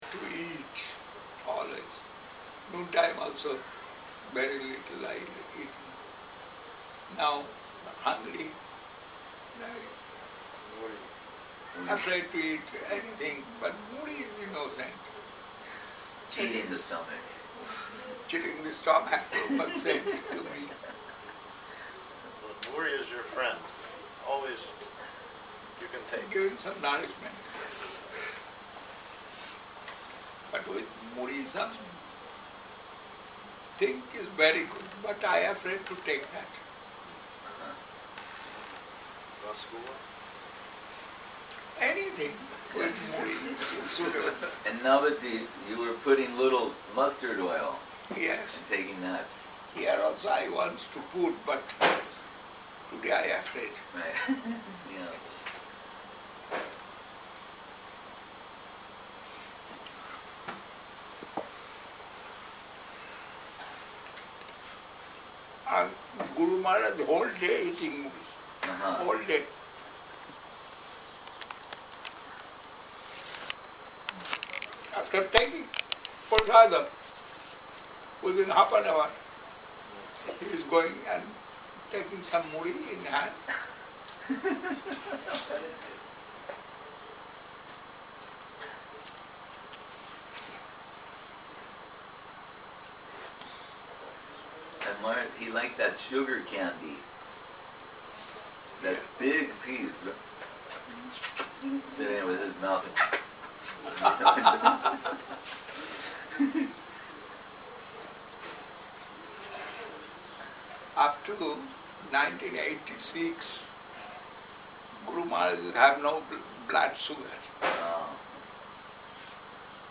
Мое сердце принадлежит Кришне Часть 1 Простая беседа
Place: Sri Chaitanya Saraswat Math Saint-Petersburg